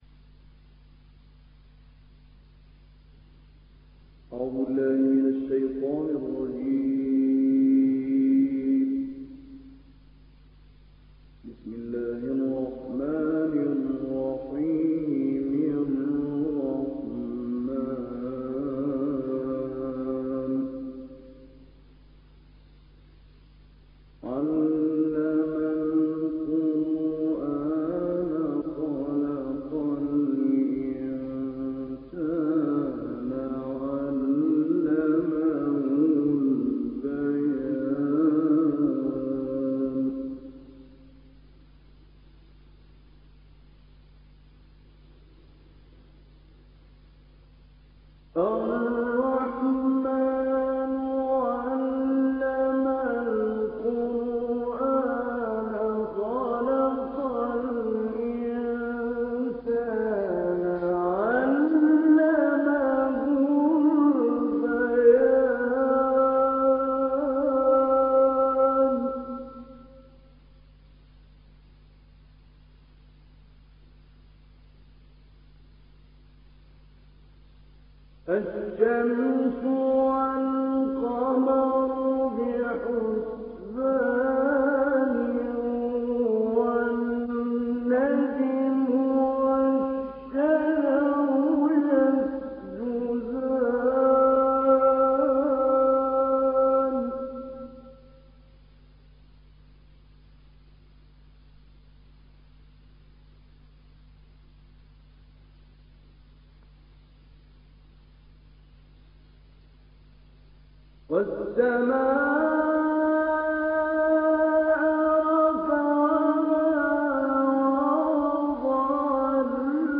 تسجيلات ستوديو